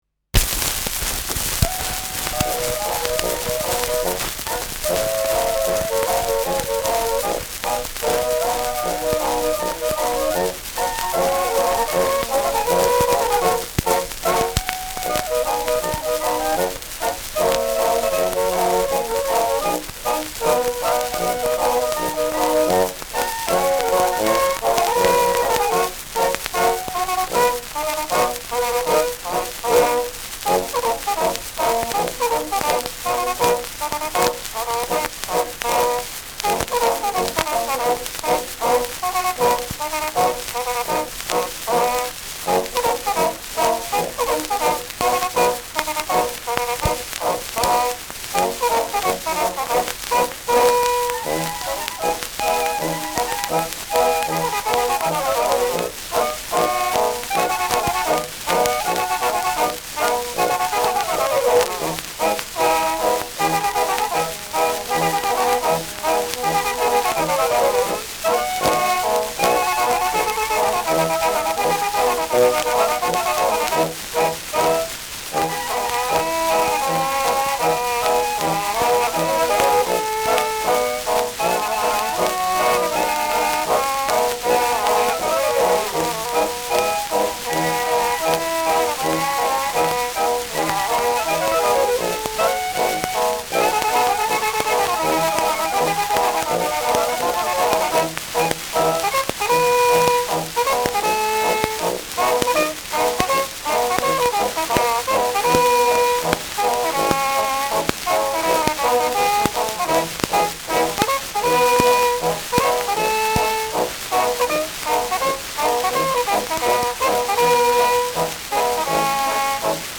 Schellackplatte
starkes Rauschen : präsentes Knistern : leiert : abgespielt : gelegentliches Knacken : Knacken ab 1’35’’